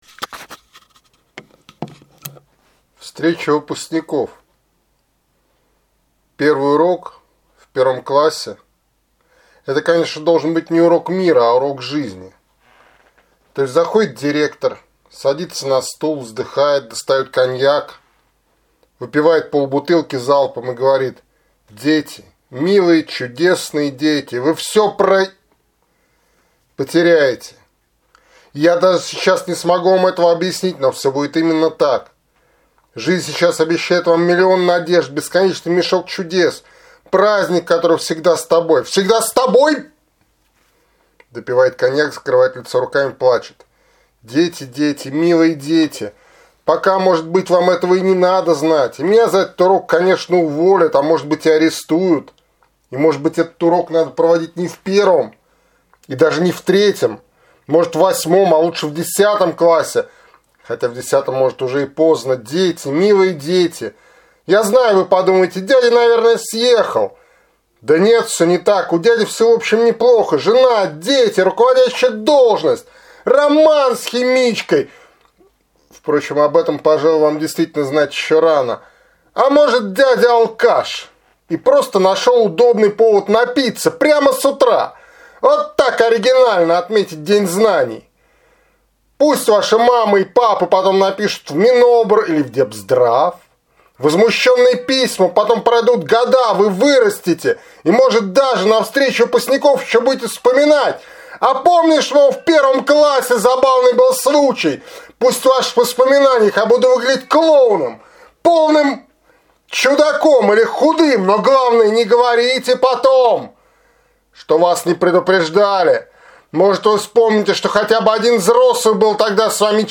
читает стихотворение «Встреча выпускников»